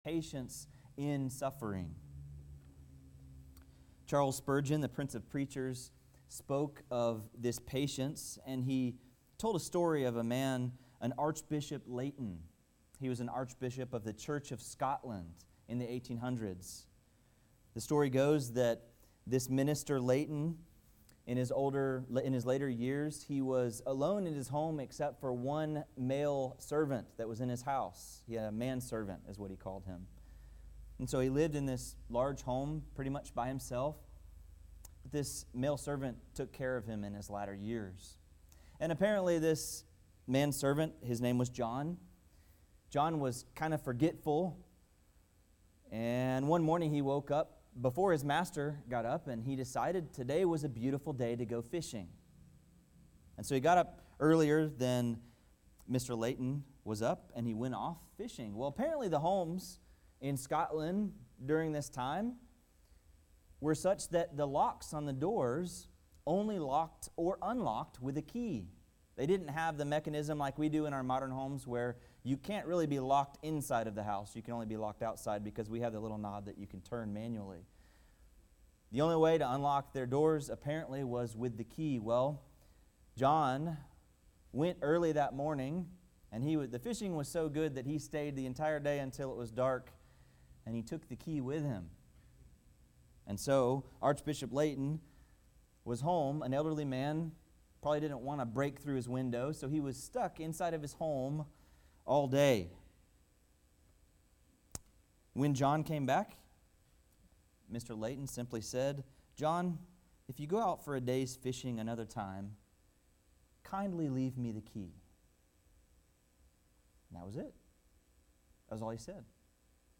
Podcast (grace-covenant-hillard-sermons): Play in new window | Download